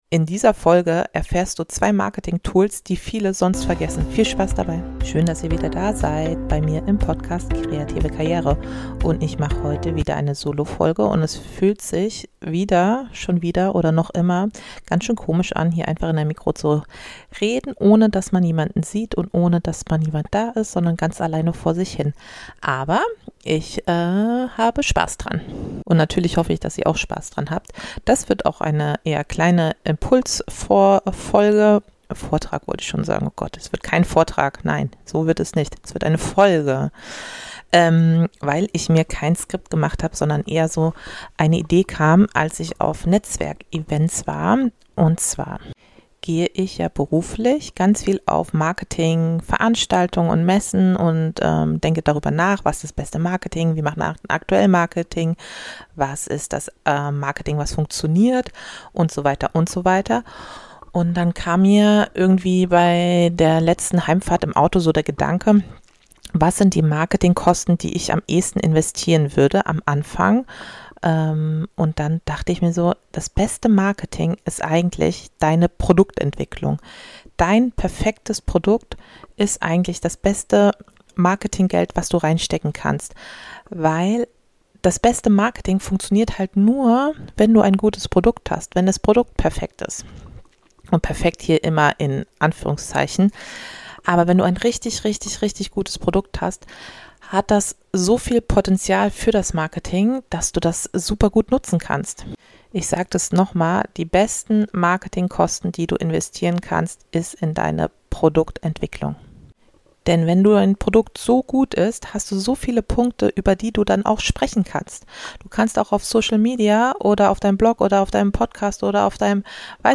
In dieser Solo-Folge spreche ich über zwei unterschätzte Bausteine, die mehr über deinen Erfolg entscheiden als jeder Social-Media-Algorithmus: